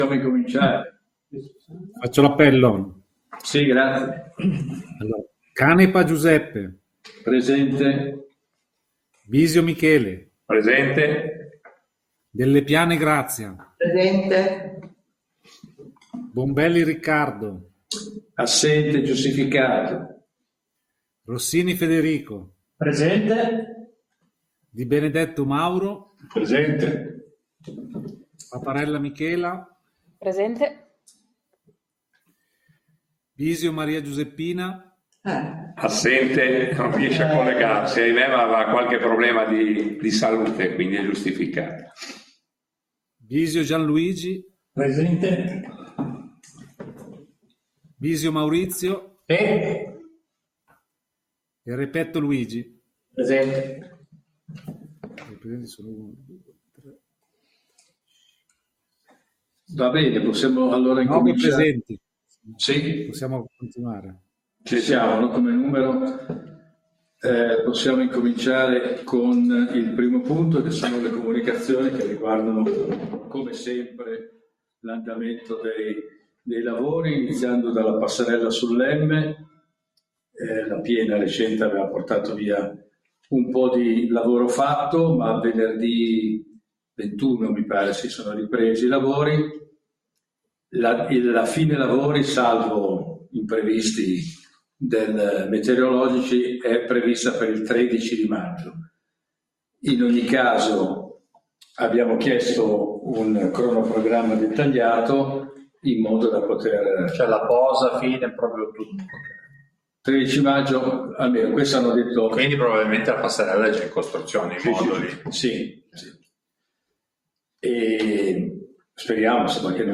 Seduta del Consiglio Comunale del 24/02/2025